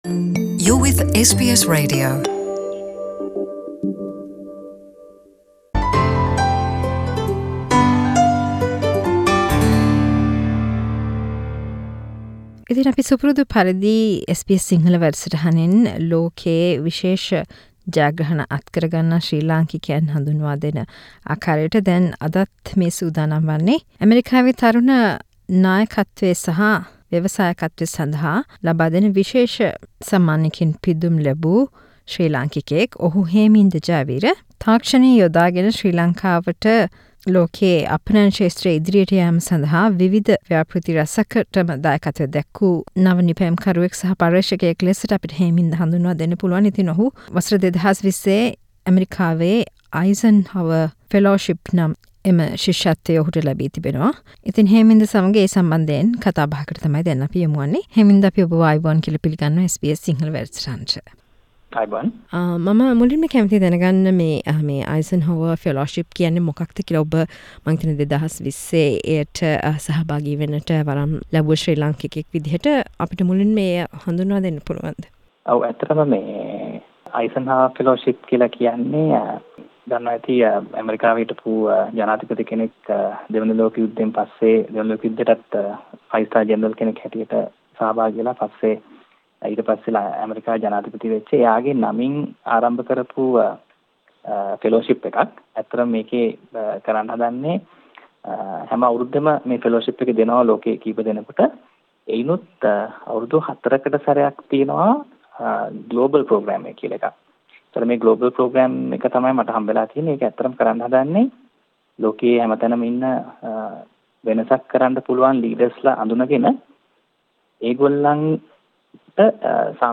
A chat